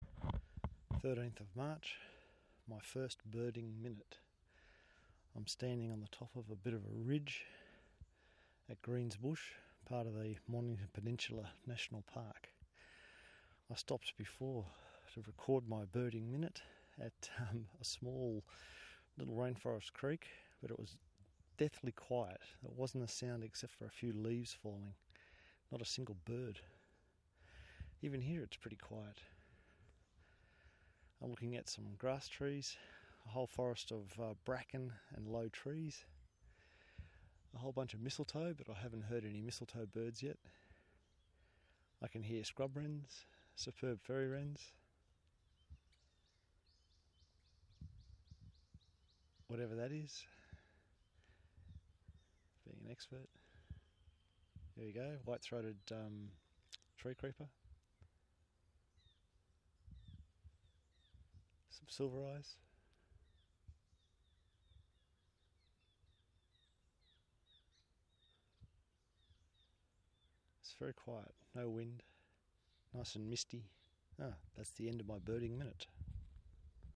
The concept is about recording a site and the experience of that moment, the conditions, location and sounds of the local birds. For my first minute I thought I would use my iPhone and a Rode mic but it did not turn out well so in future I will record using my Zoom and the Rode mic. I recorded my 1st Birding Minute last weekend at Greens Bush on the Mornington Peninsula. It is a favourite spot of mine for an early morning walk amongst varied forest and rainforest vegetation types and usually has many birds but on this occasion it was very still and quiet.